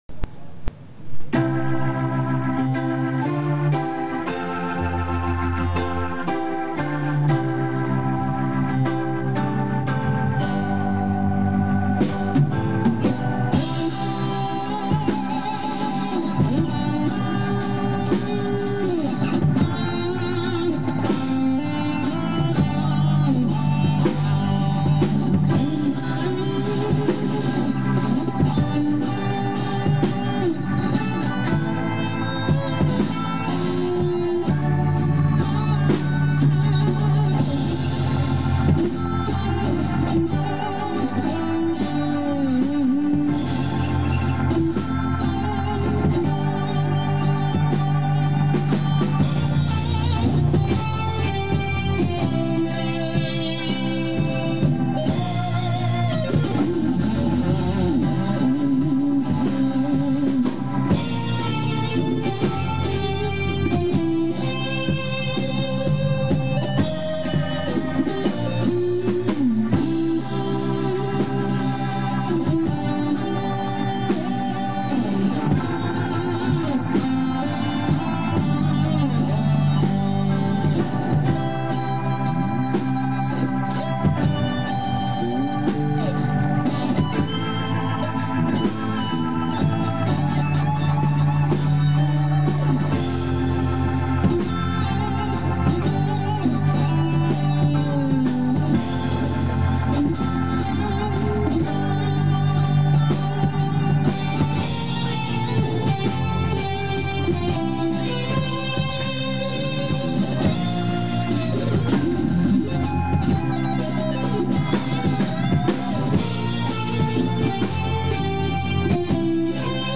２・３曲目とメドレーになっています。
しかもリズムが６／８拍子というリズムなので
コーラスエフェクトをかけるタイミングも失敗しました。
私は普通のビブラートの所はハンドビブラートで代用しています。
まあ、熱かったしチューニングやピッチも狂っては来ます。
所々、細かいビブラートをかけていますが、
哀愁漂う繊細な泣きが、ココキンでした